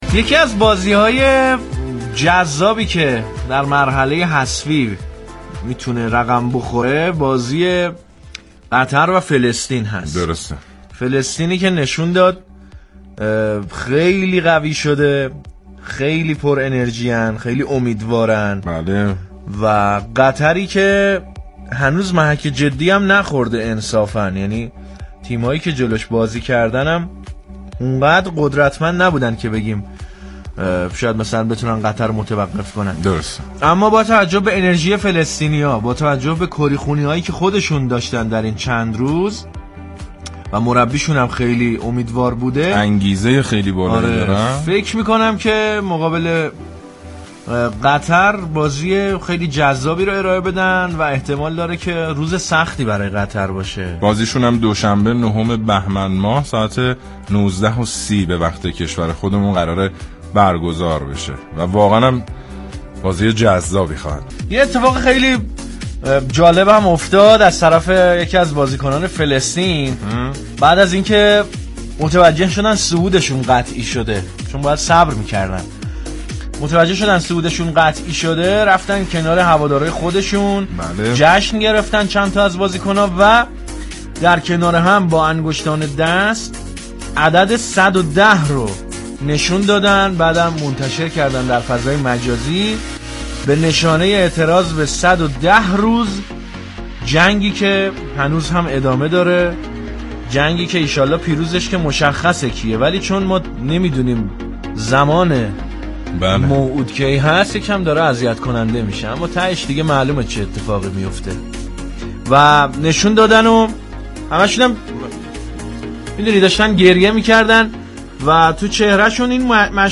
«دو آتیشه» برنامه ای شاد و مهیج برای بازتاب حواشی و اتقافات هجدهمین جام ملت های آسیا در قطر است، این برنامه در كنار هواداری دو آتیشه تیم ملی به صورت ویژه مخاطبان را در جریان اخبار و حواشی مربوط به تیم ملی فوتبال فلسطین قرار می دهد.